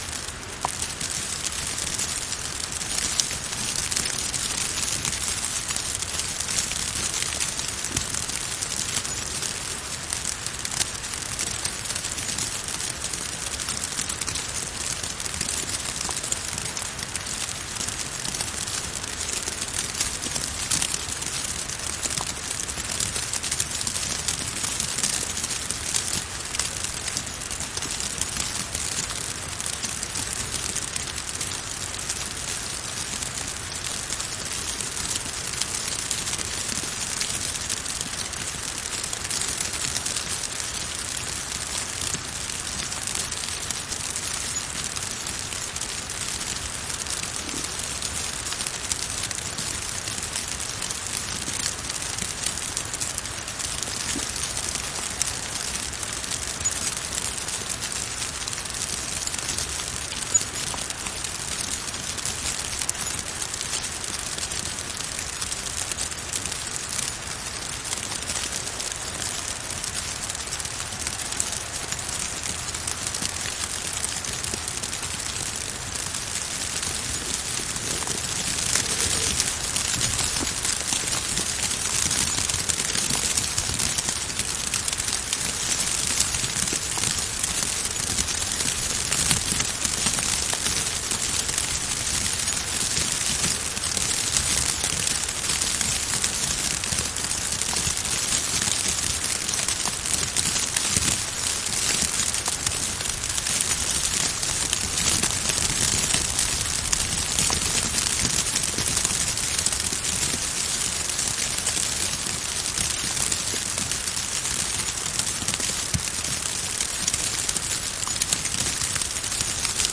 snow.ogg